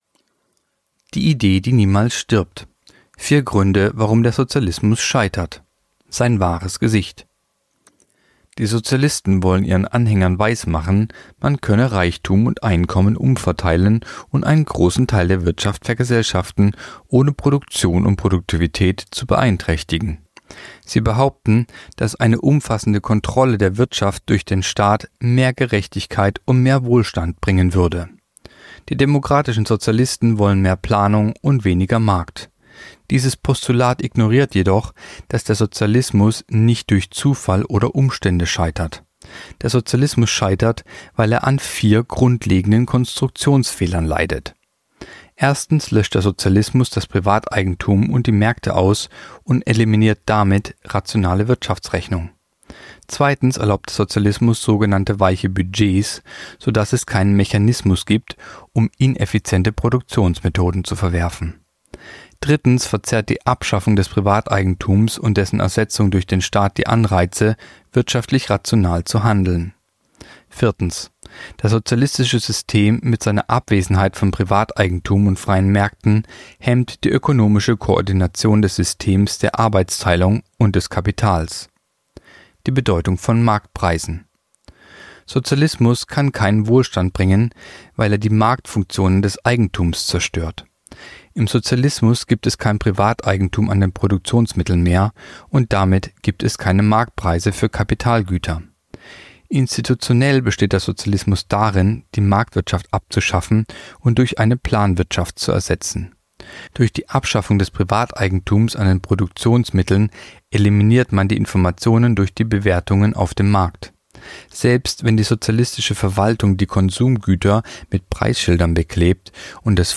Kolumne der Woche (Radio)Vier Gründe, warum der Sozialismus scheitert